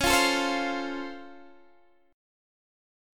Db+M7 chord